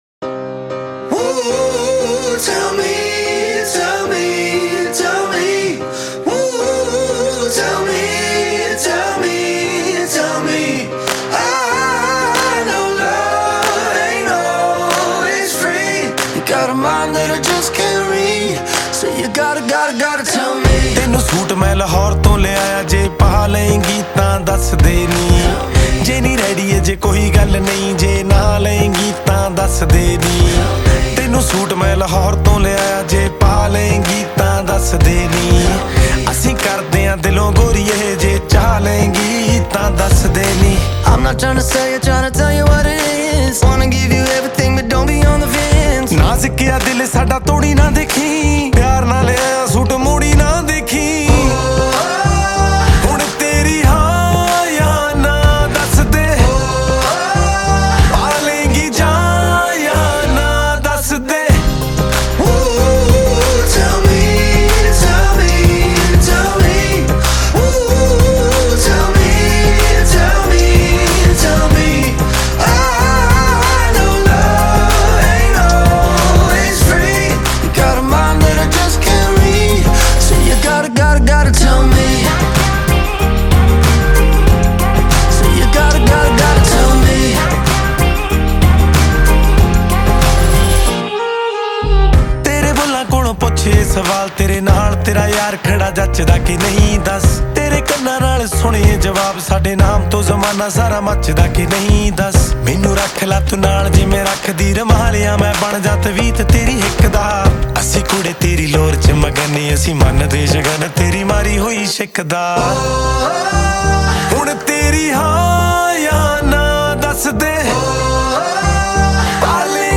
Releted Files Of Punjabi